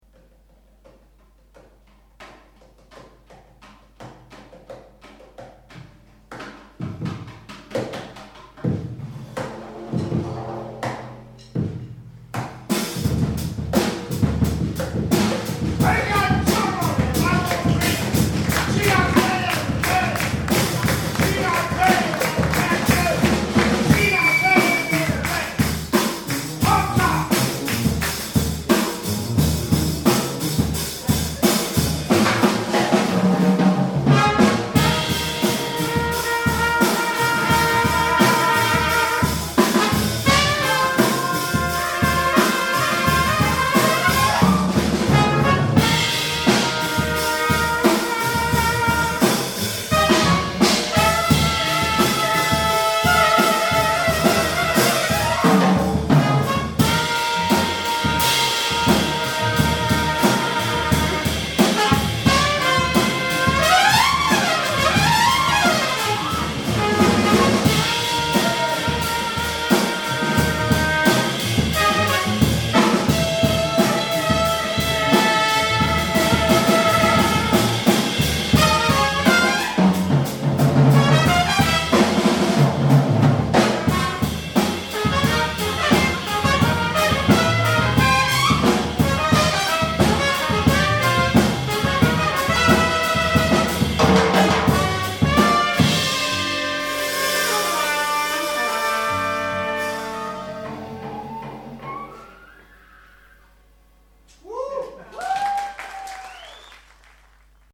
Schlagzeug, Percussion
Trompete
Kontrabass
Euphonium, Trompete